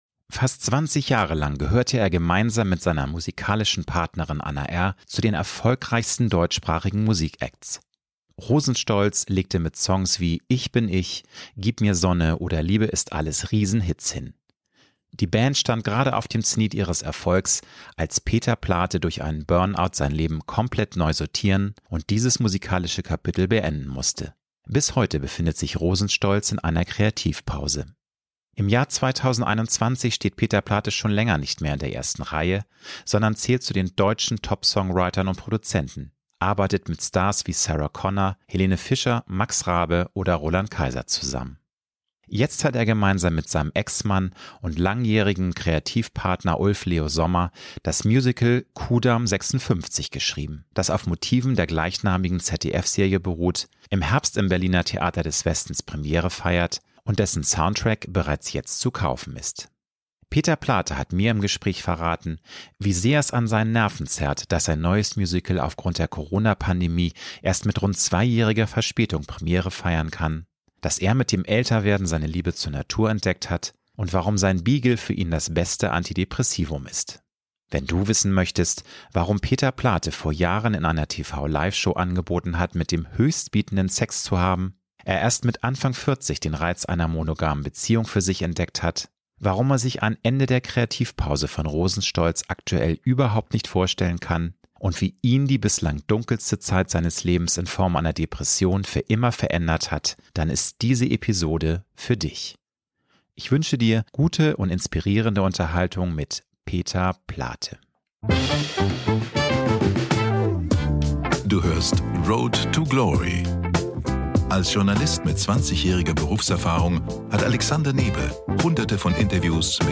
Promi-Talk